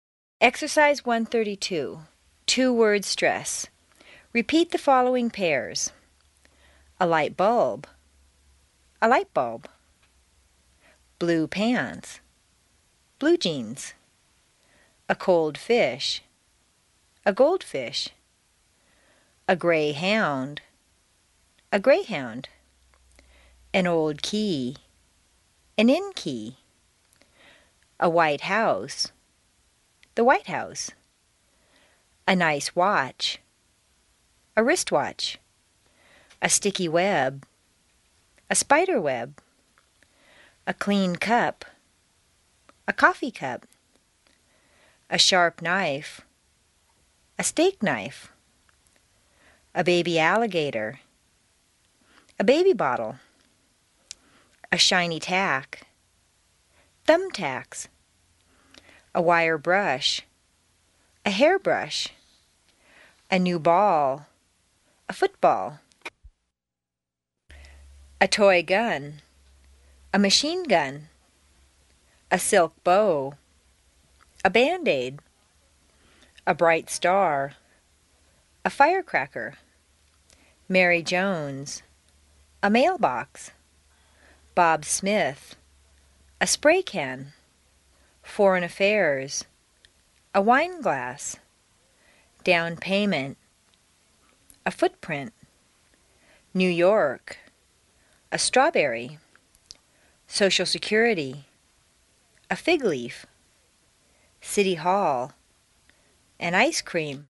美语口语发音训练 第一册45_恒星英语
Exercise 1-32: Two-Word Stress CD 1 Track 45